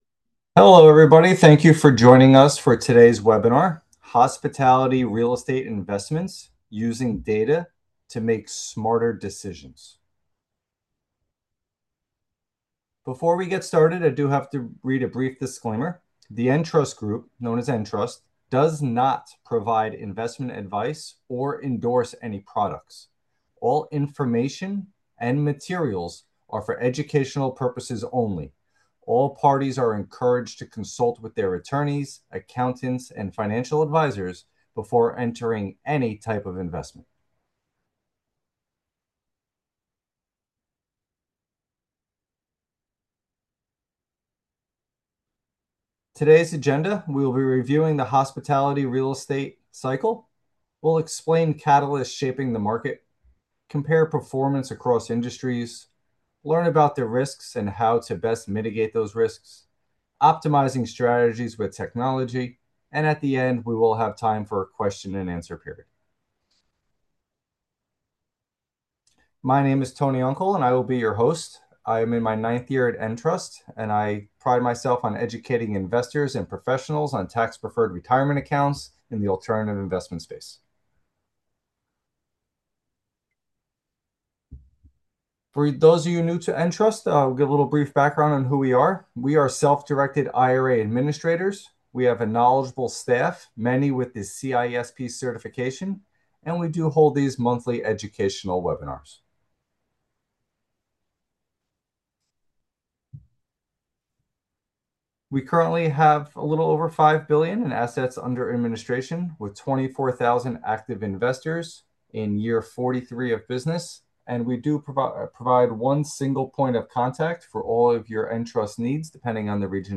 In this educational training, we'll provide an introduction to hospitality real estate investing, including data-driven insights on today's market challenges providing opportunities, industry historical timeline, identifying risks, and more.